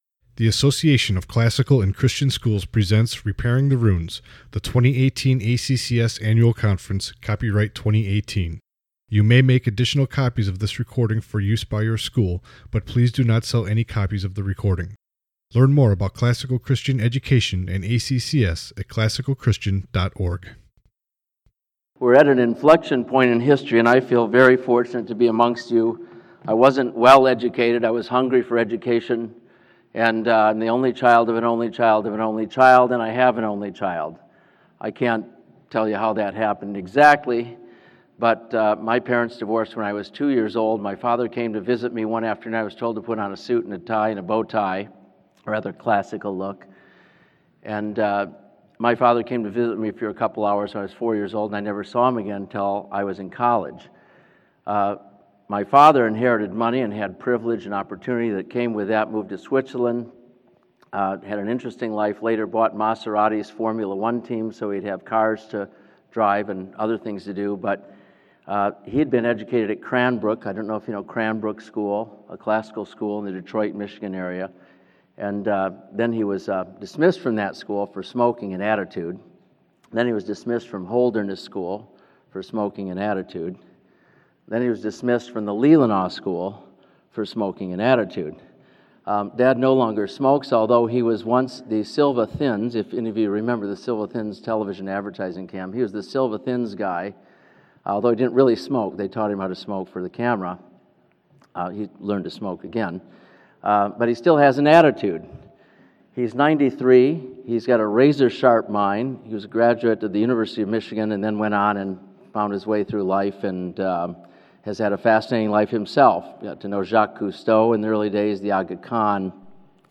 2018 Leaders Day Talk | 45:53 | Leadership & Strategic